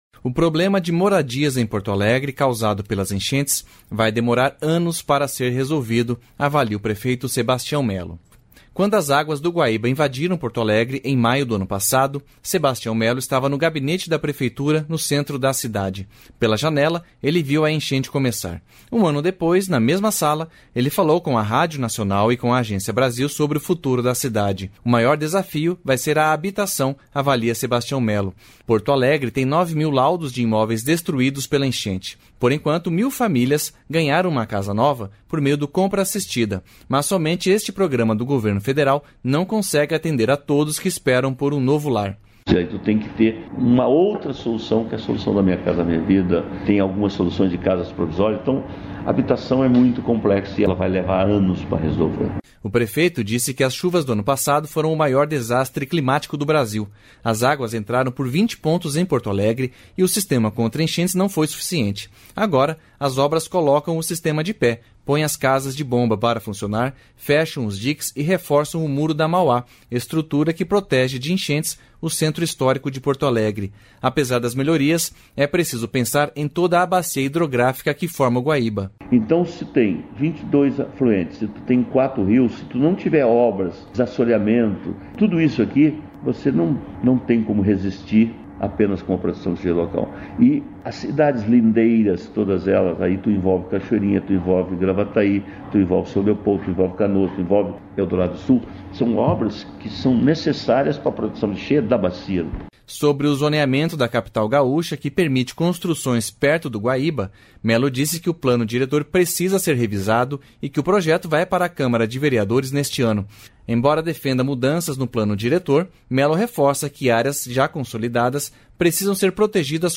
Um ano depois, na mesma sala, ele falou com a Rádio Nacional e com a Agência Brasil sobre o futuro da cidade.